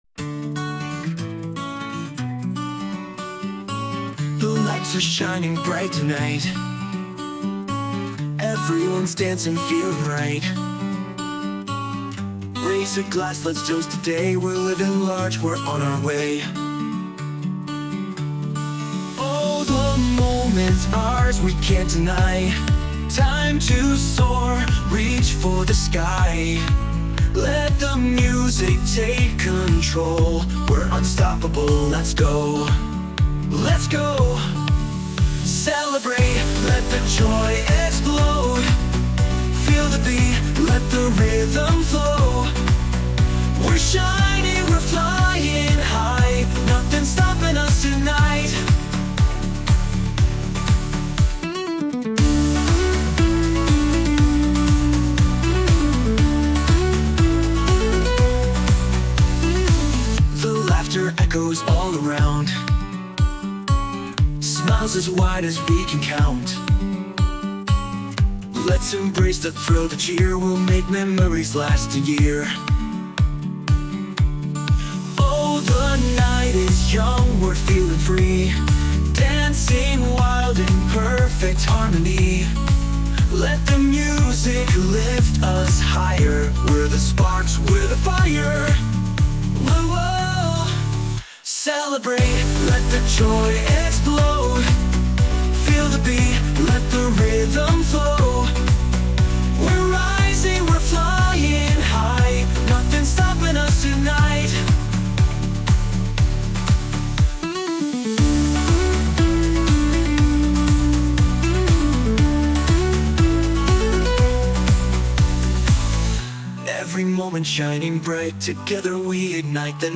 著作権フリーオリジナルBGMです。
男性ボーカル（洋楽・英語）曲です。
結婚式、その他に関係なく「お祝い」をテーマにしたエネルギッシュな曲にしたくて制作しました！